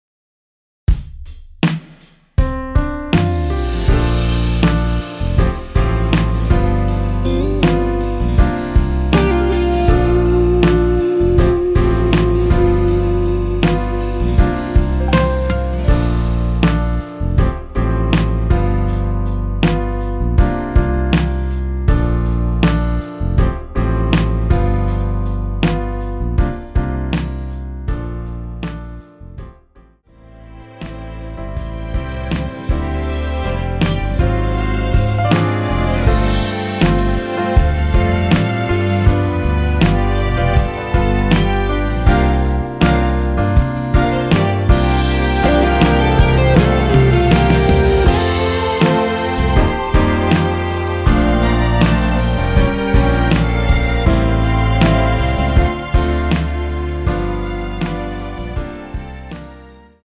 원키에서 (+1)올린 MR 입니다.(미리듣기 확인)
앞부분30초, 뒷부분30초씩 편집해서 올려 드리고 있습니다.